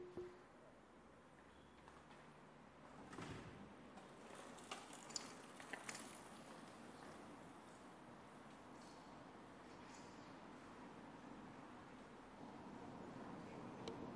交通声音 " 自行车链轮旋转
Tag: 嗡嗡 自行车 自行车